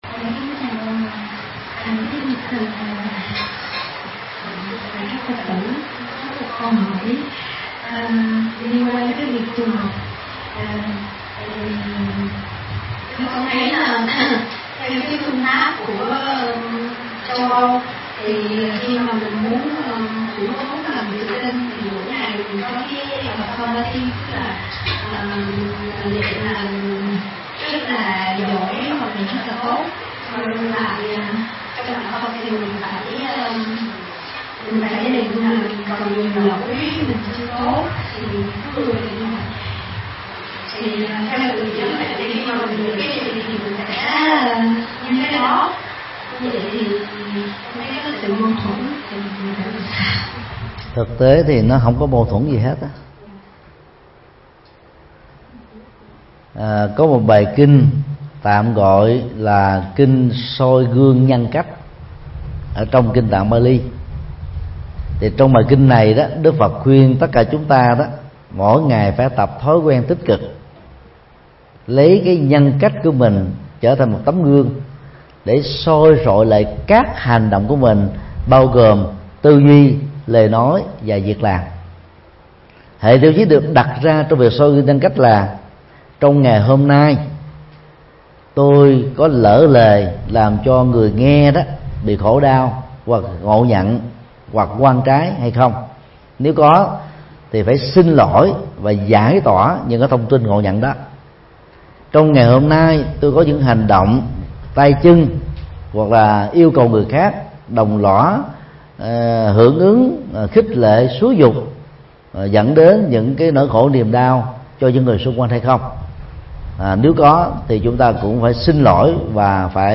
Vấn đáp: Soi gương nhân cách, việc học Phật pháp
Giảng tai chùa Linh Phong,Thụy Sĩ,ngày 4 tháng 7 năm 2015